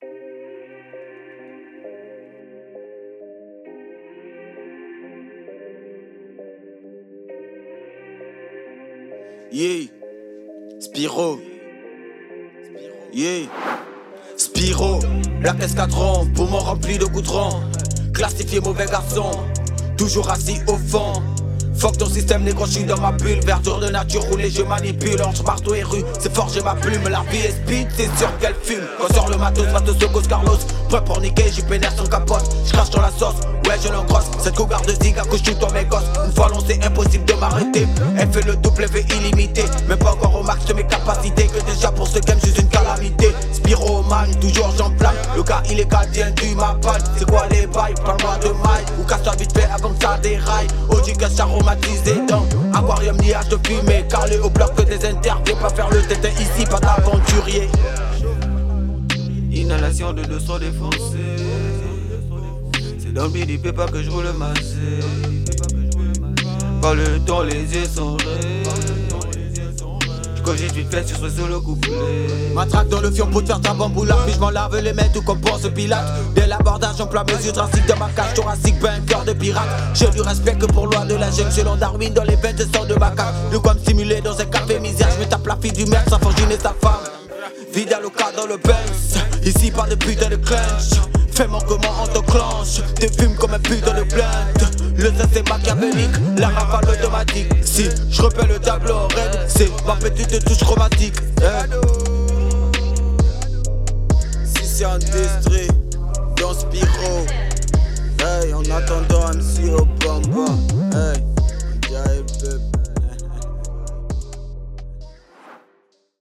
Genre : HipHop